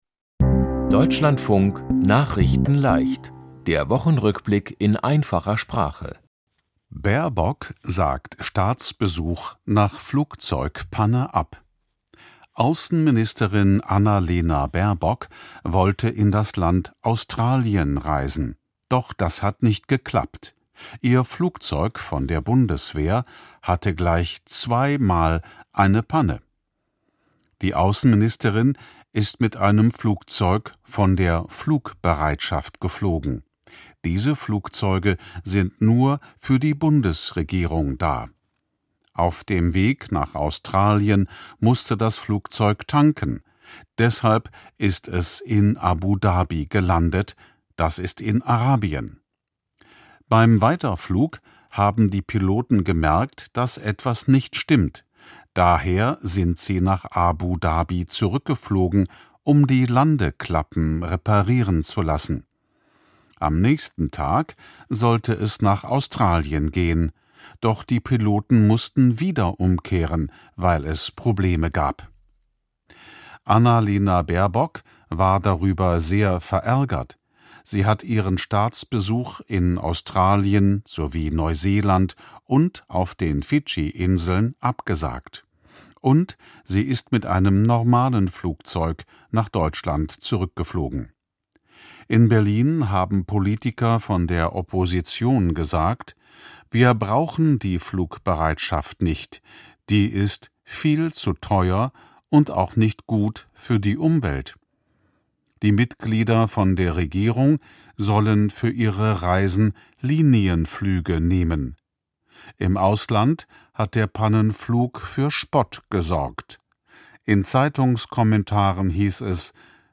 Die Themen diese Woche: Baerbock sagt Australien-Reise nach Flugzeug-Panne ab, Streit in der Regierung, Regierung will Cannabis erlauben, Zu wenig Inklusion in Deutschland, Feuer auf Hawaii , Dirk Nowitzki in die „Hall of Fame“ aufgenommen. nachrichtenleicht - der Wochenrückblick in einfacher Sprache.